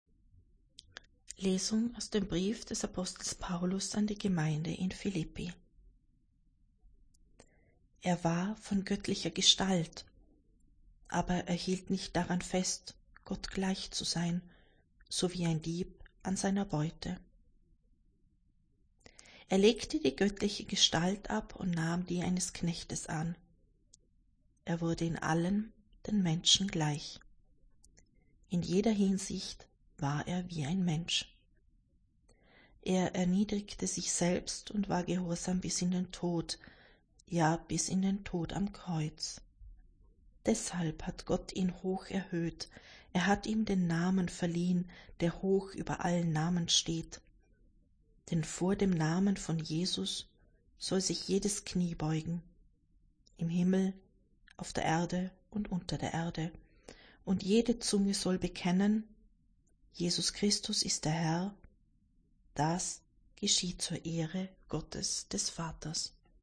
Wenn Sie den Text der 2. Lesung aus dem Brief des Apostels Paulus an die Gemeinde in Philíppi anhören möchten: